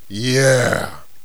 warrior_ack3.wav